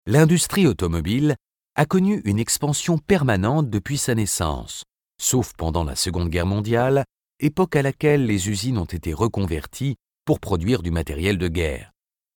Voix françaises
Nos comédiens adapteront leur jeu en fonction de votre texte.
Voix masculines